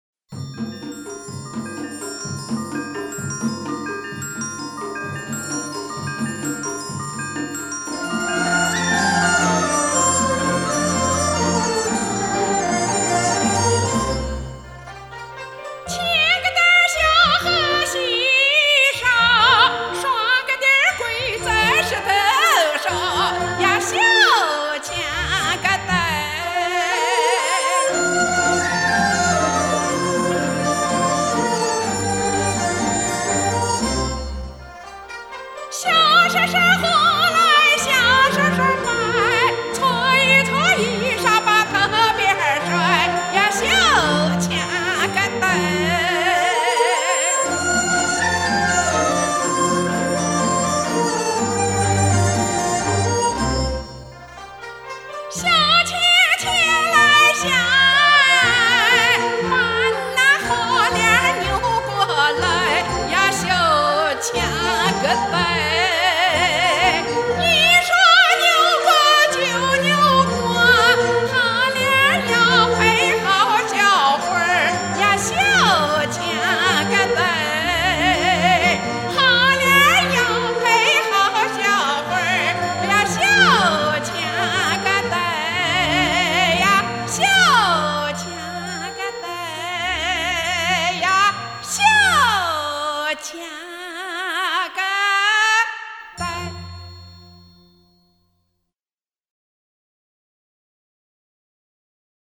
左权民歌